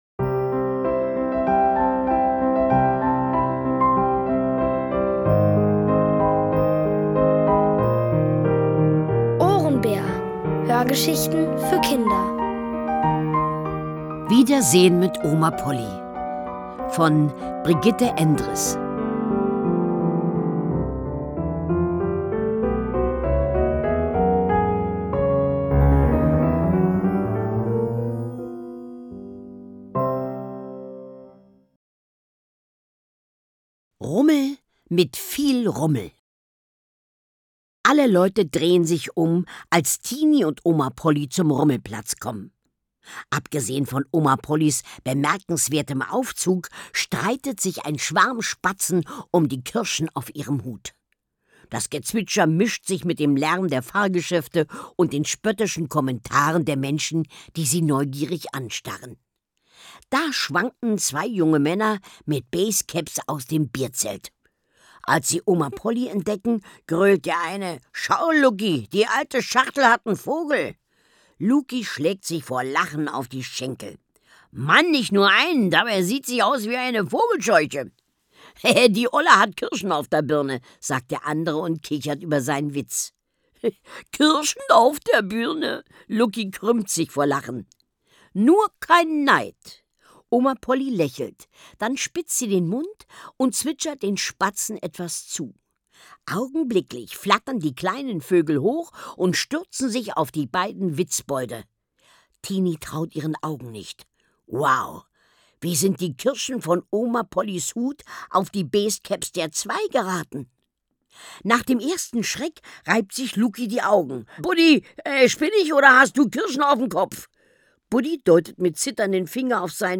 Von Autoren extra für die Reihe geschrieben und von bekannten Schauspielern gelesen.
Es liest: Carmen-Maja Antoni.